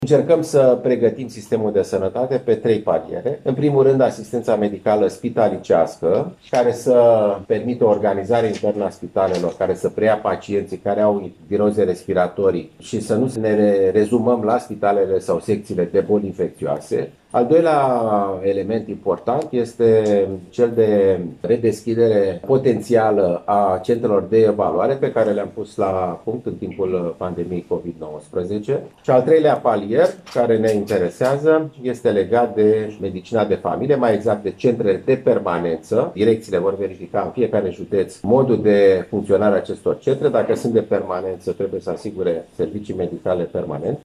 Alexandru Rafila a explicat, într-o videoconferință cu șefii direcțiilor de sănătate publică din țară, că în acest moment, ar trebui redeschise centrele de evaluare cu servicii ambulatorii, astfel încât să se evite blocarea unităților de primiri urgențe.